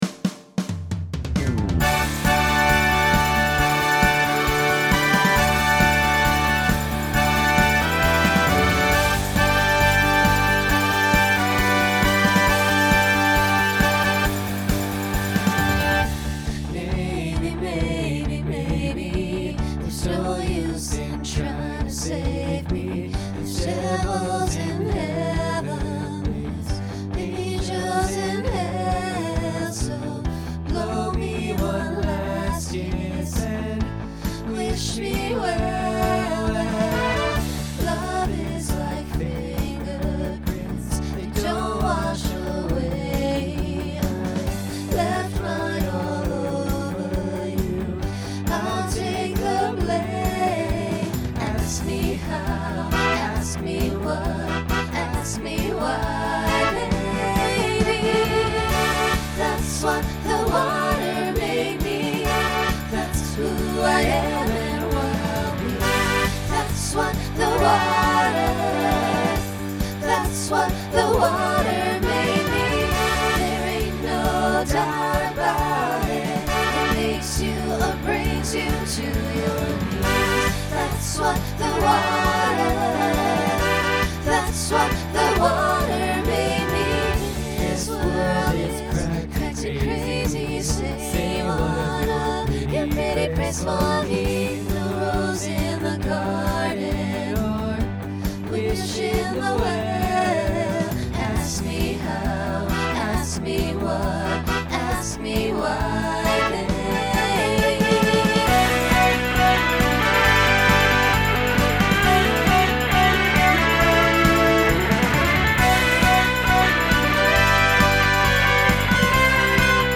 Genre Rock
Mid-tempo , Opener Voicing SATB